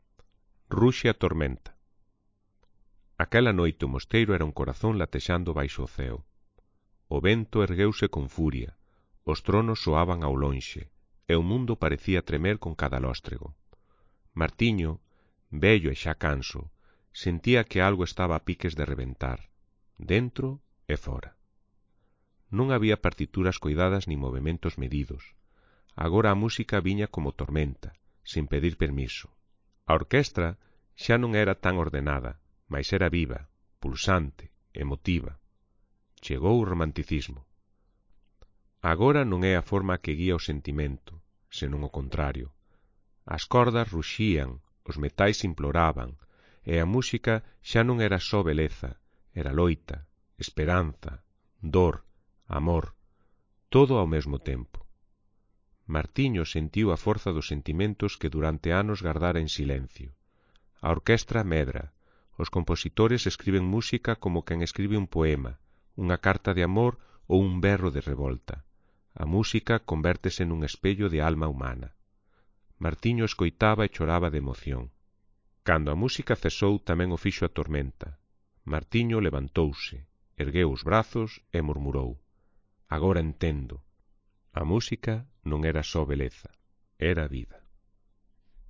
Ruxe_a_tormenta_-_baixa_calidade.mp3